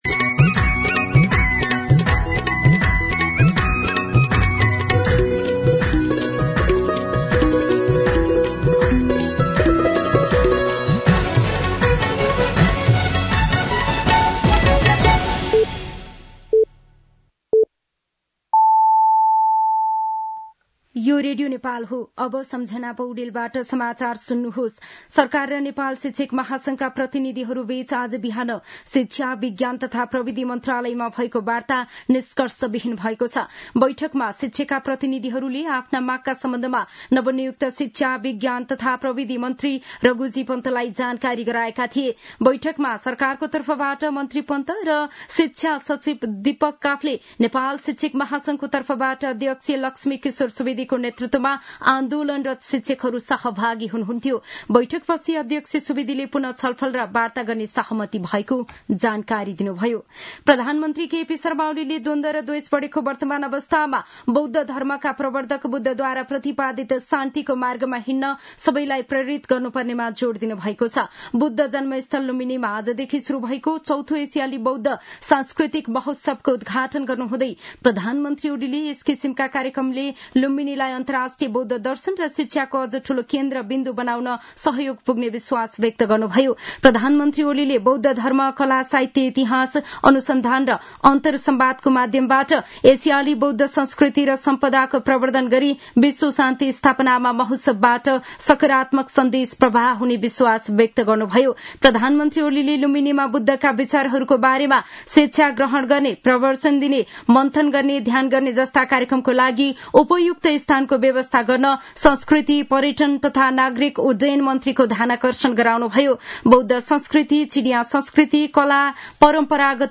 दिउँसो ४ बजेको नेपाली समाचार : १३ वैशाख , २०८२